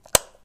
switch31.wav